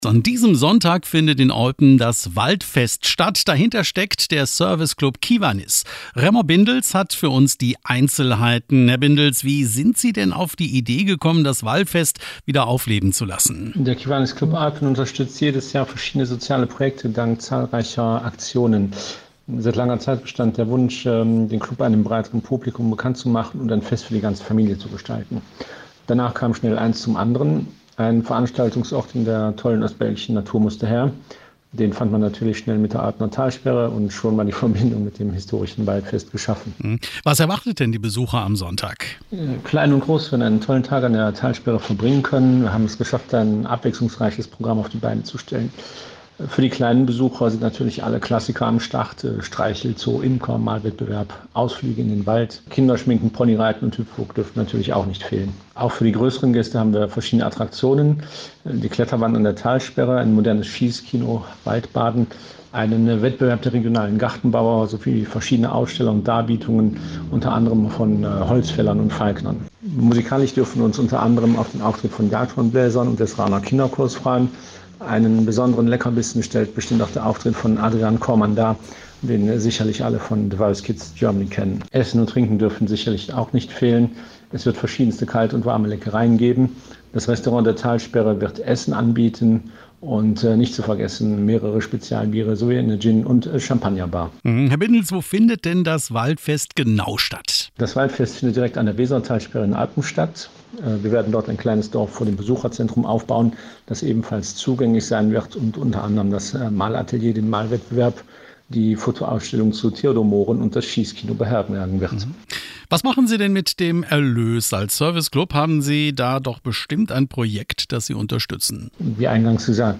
Waldfest-Interveiw.mp3